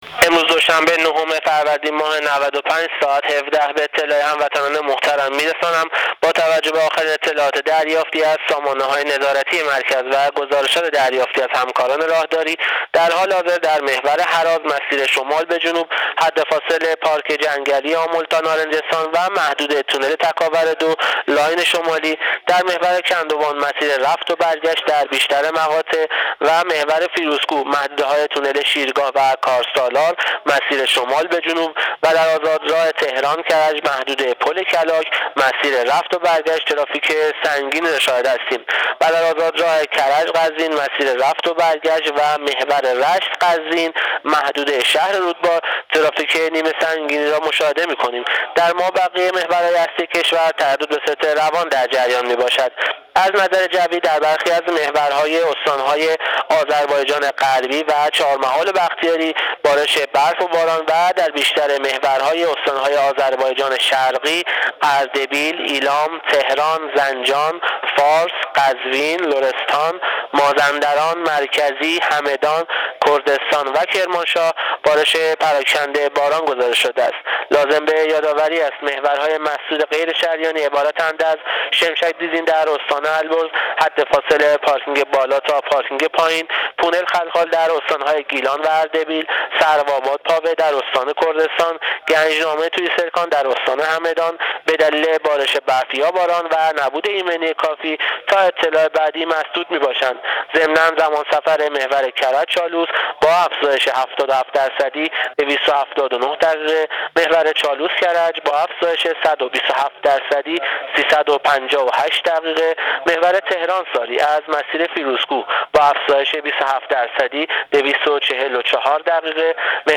رادیو اینترنتی پایگاه خبری وزارت راه و شهرسازی؛